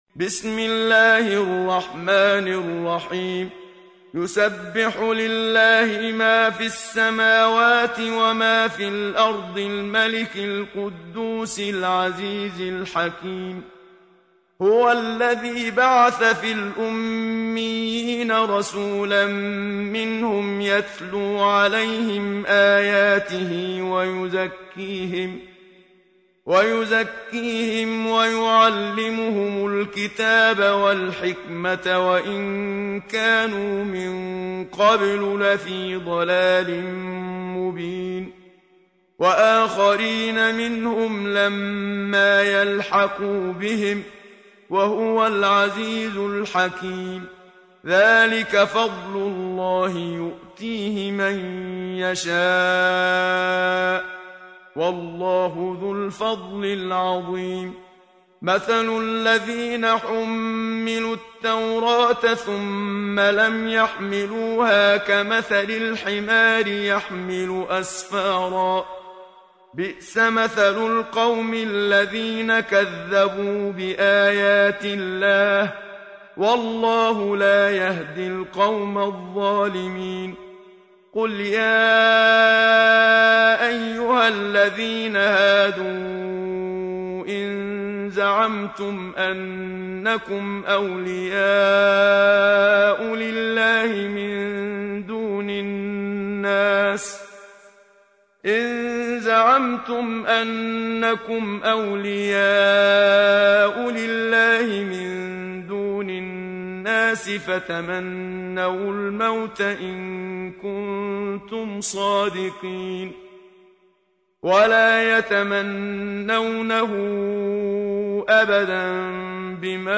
سورة الجمعة | القارئ محمد صديق المنشاوي